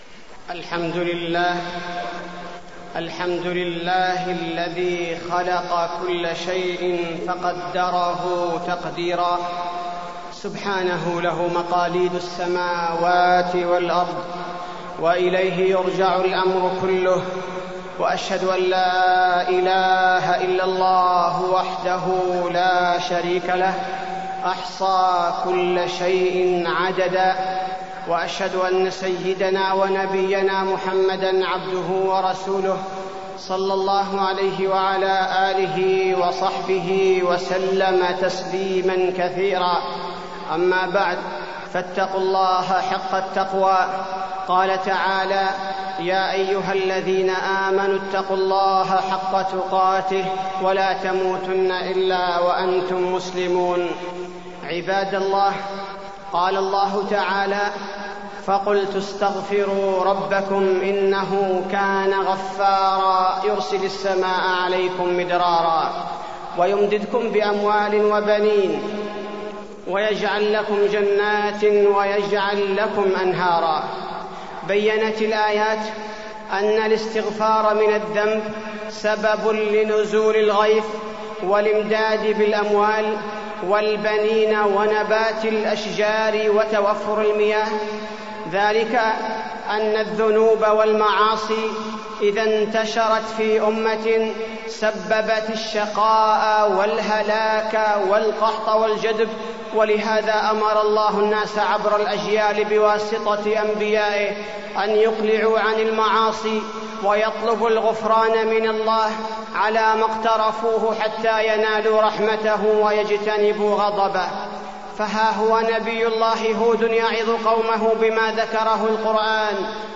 خطبة الاستسقاء - المدينة- الشيخ عبدالباريء الثبيتي - الموقع الرسمي لرئاسة الشؤون الدينية بالمسجد النبوي والمسجد الحرام
تاريخ النشر ١٦ رمضان ١٤٢٣ هـ المكان: المسجد النبوي الشيخ: فضيلة الشيخ عبدالباري الثبيتي فضيلة الشيخ عبدالباري الثبيتي خطبة الاستسقاء - المدينة- الشيخ عبدالباريء الثبيتي